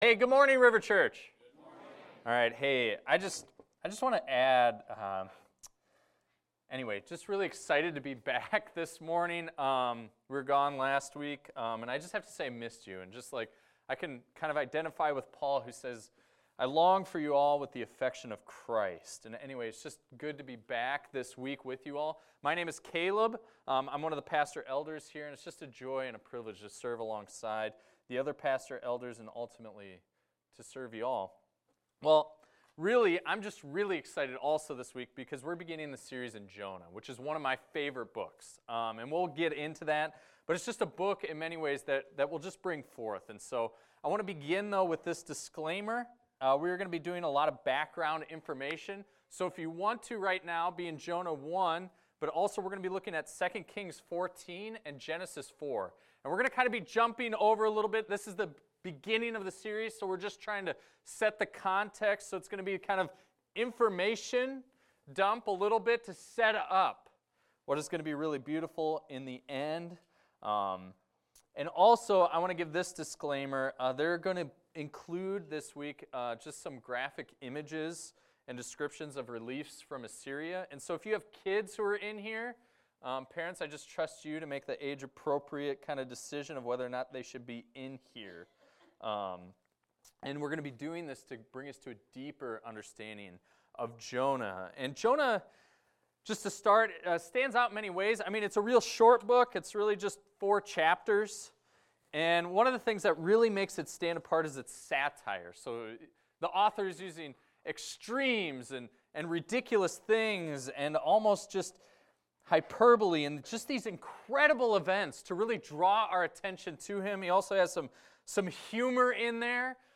This is a recording of a sermon titled, "Jonah 1:1-2."